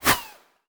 bullet_flyby_fast_07.wav